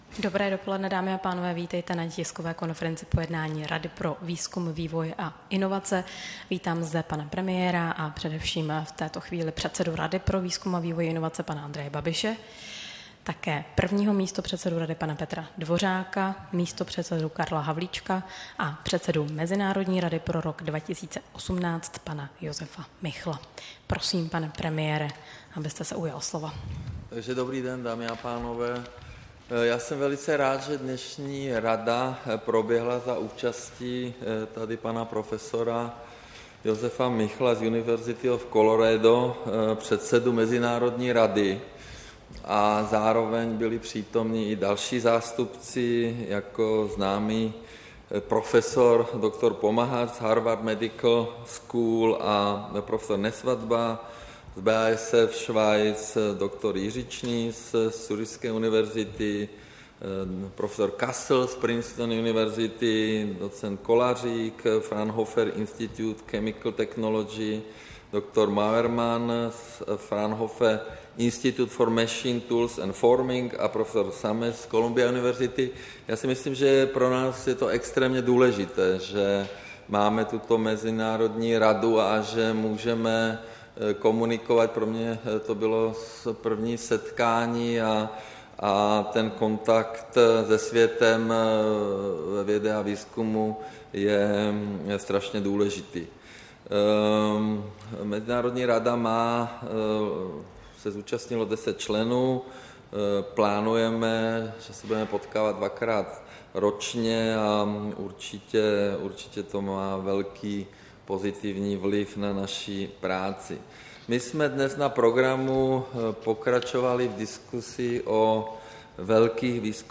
Tisková konference po jednání Rady pro výzkum, vývoj a inovace, 25. května 2018